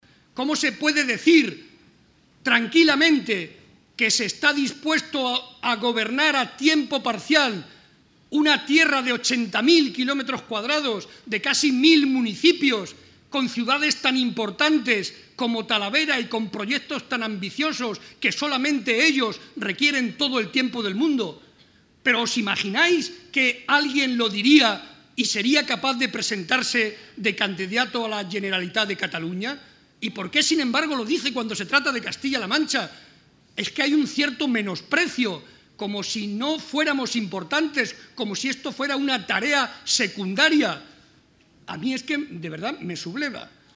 Barreda junto a Rivas en el acto celebrado en Talavera.
Barreda hizo estas manifestaciones en Talavera de la Reina, durante la presentación de la candidatura a la Alcaldía que encabeza José Francisco Rivas y que ha tenido lugar en el Instituto “Juan Antonio Castro”, donde el actual alcalde se ha formado.